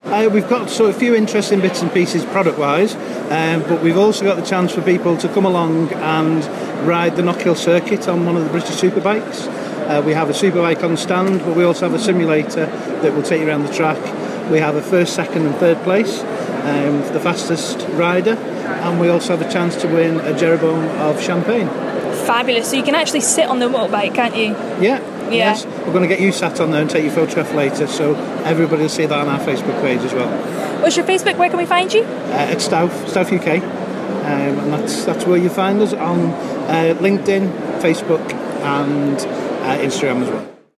Northsound 1 is providing a flavour of Offshore Europe 2017.